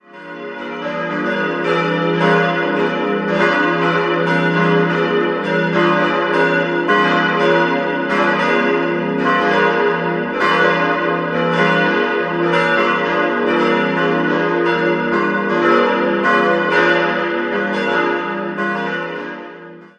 Dies, und der Wunsch nach Schaffung einer Werktagskapelle, machten wieder einen größeren Umbau nötig. 4-stimmiges Geläute: d'-f'-as'-b' Alle Glocken wurden 1924 vom Bochumer Verein für Gussstahlfabrikation gegossen.